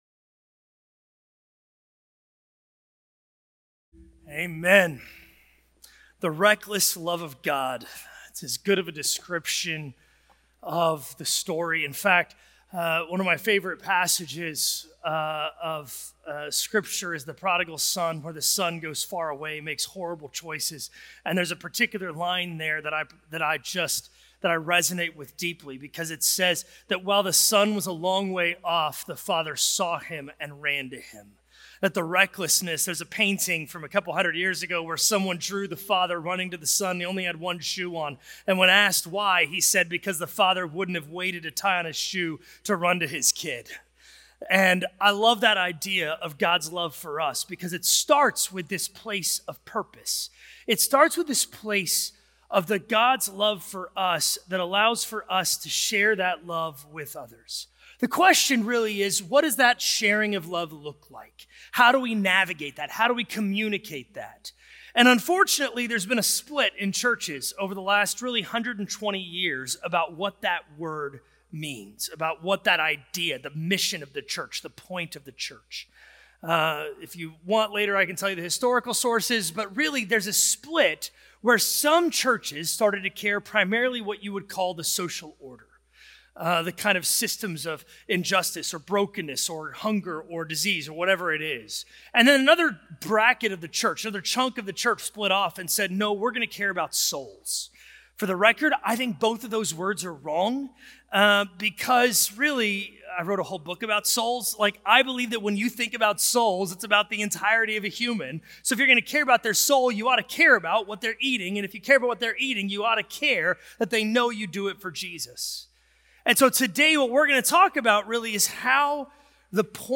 A message from the series "Missio Dei."